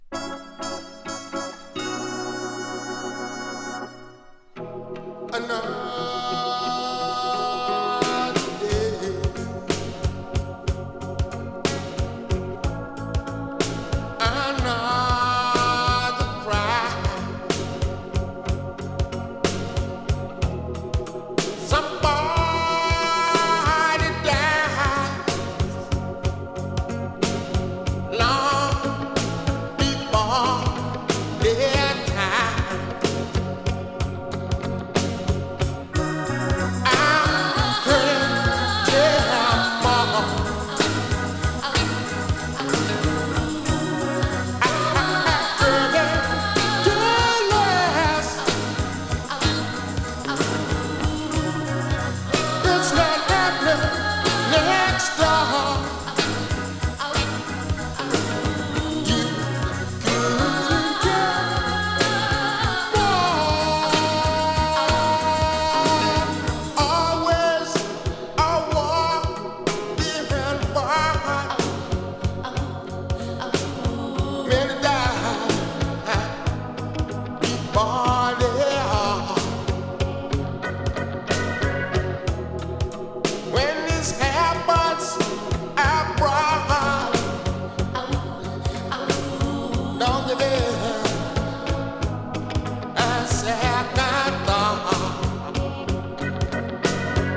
60's Soul感がサイコー。